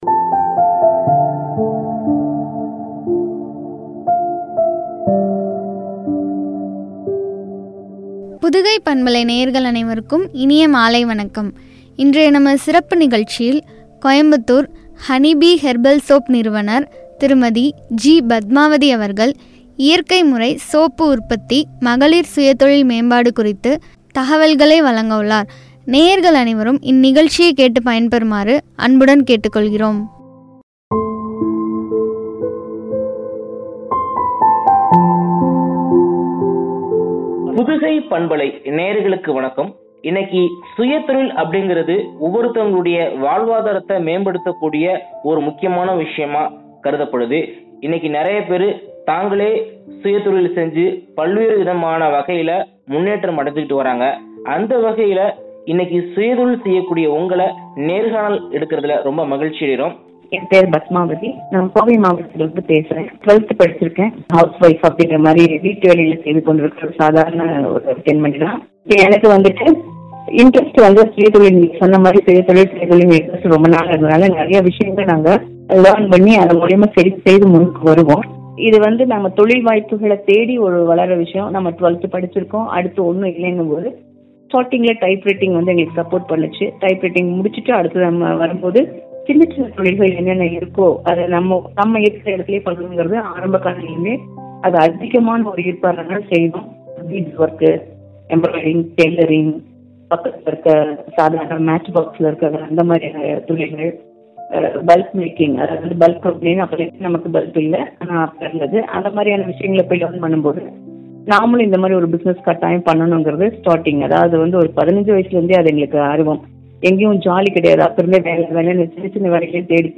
மகளிர் சுய தொழில் மேம்பாடும்” குறித்து வழங்கிய உரையாடல்.